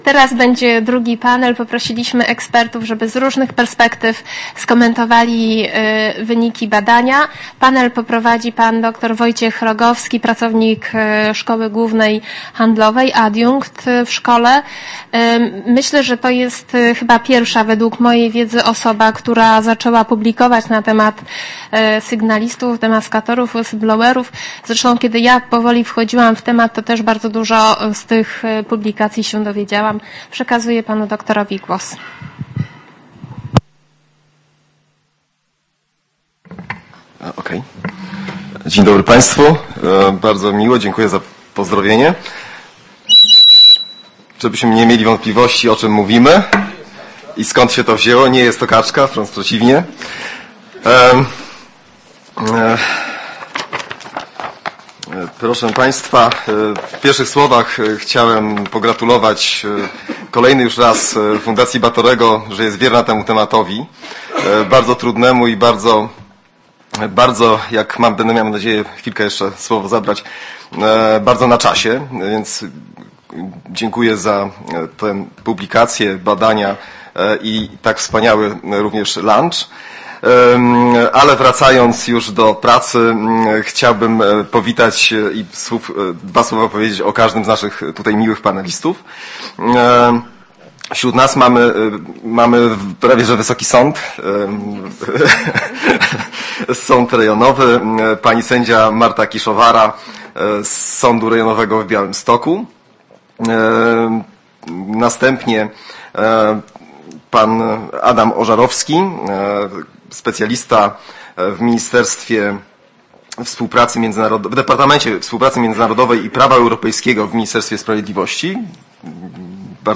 – zapis dźwiękowy I części dyskusji (1 godz. 50 minut) >>> – zapis dźwiękowy panelu eksperckiego (1 godz. 25 minut) >>> – A. Wojciechowska-Nowak, Ochrona prawna sygnalistów w doświadczeniu sądów pracy.